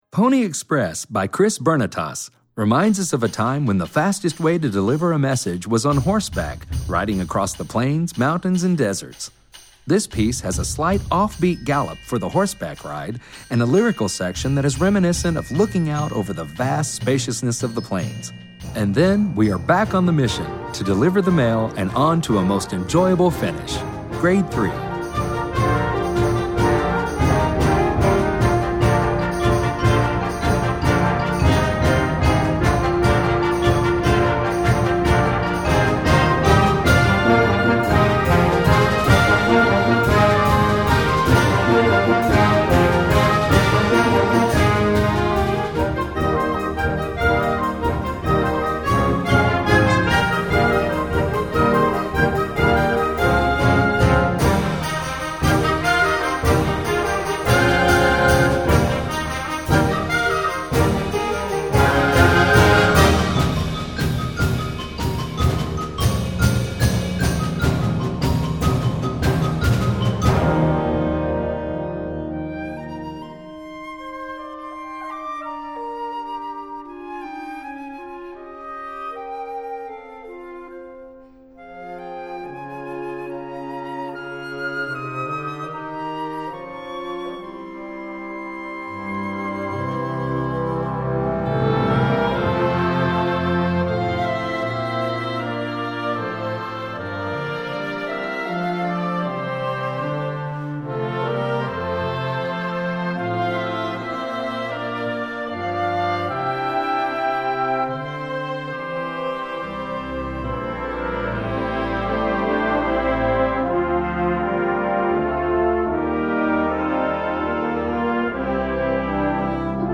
Werk für Jugendblasorchester Schwierigkeit
2:55 Minuten Besetzung: Blasorchester PDF